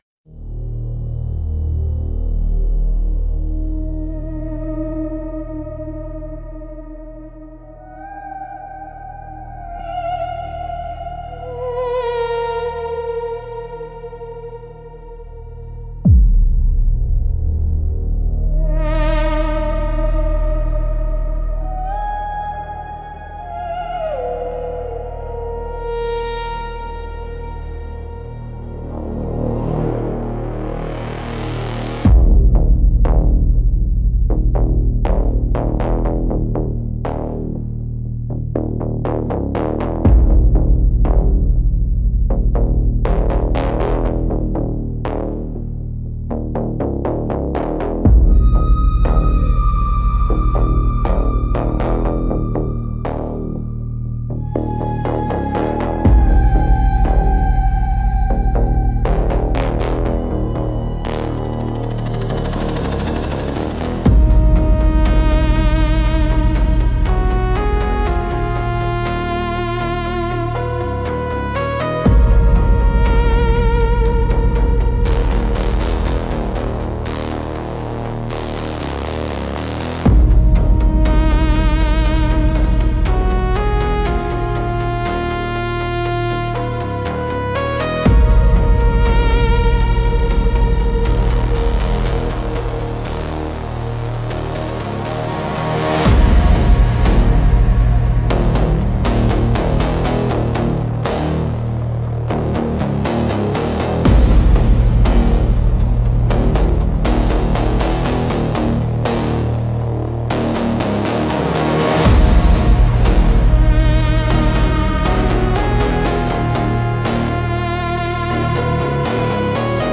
Instrumental - Beneath the Pressor Field 3.59